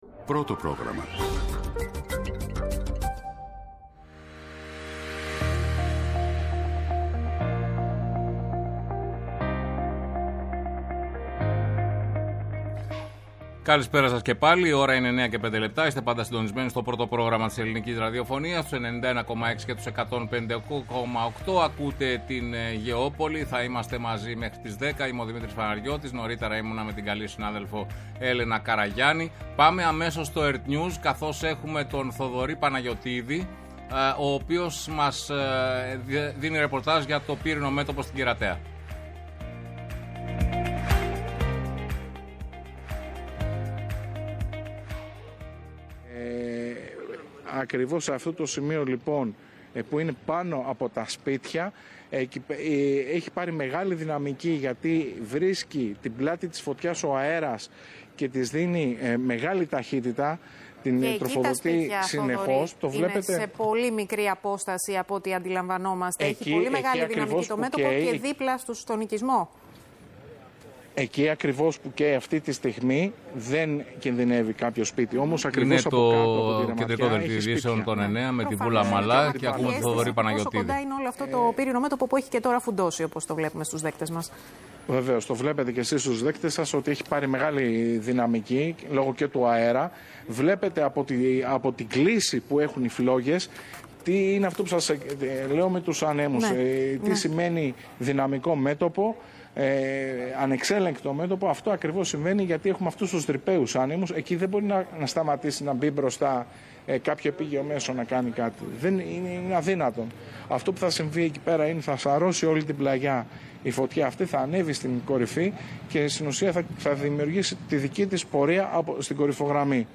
Συνδέσεις με όλα τα πύρινα μέτωπα που μαίνονται από το μεσημέρι σε πολλές περιοχές της χώρας.
-Ο Κωνσταντίνος Μιχόπουλος, Δήμαρχος Μεγαλόπολης Αρκαδίας για τη φωτιά στην περιοχή Ρούτσι